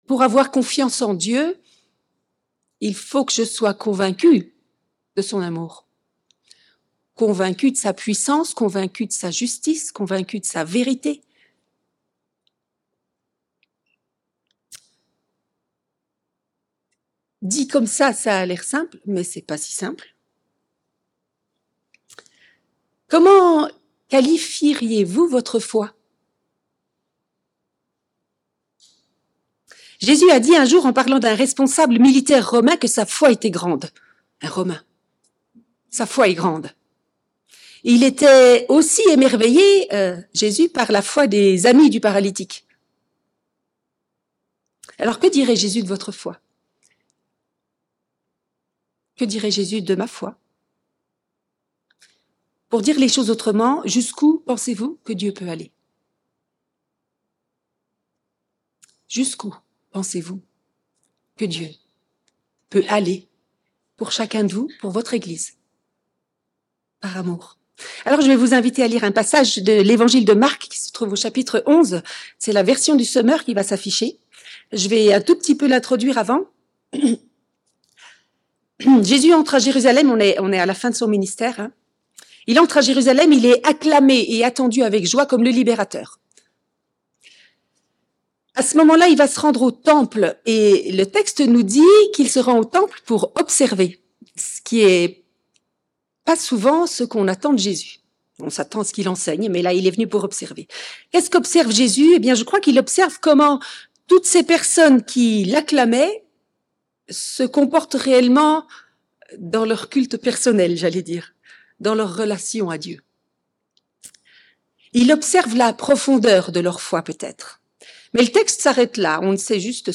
Culte hebdomadaire - EEBS - Église Évangélique Baptiste de Seloncourt